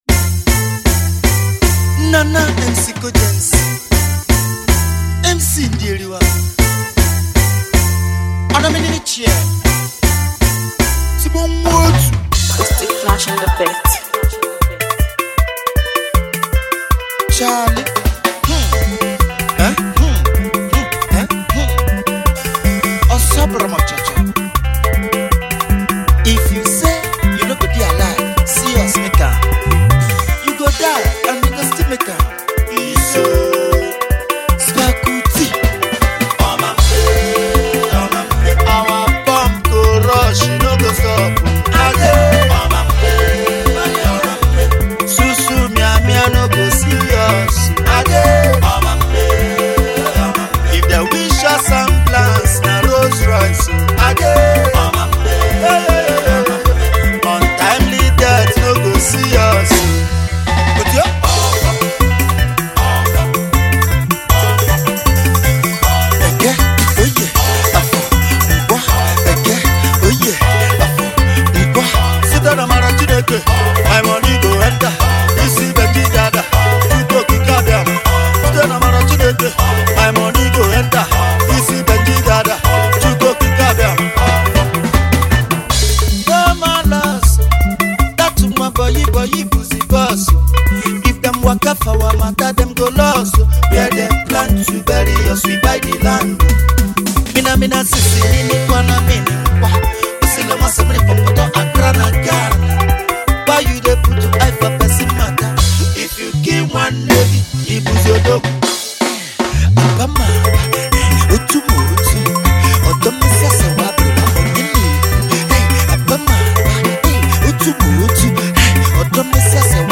Fast Rising Igbo music phenomenon
sizzling and thrilling song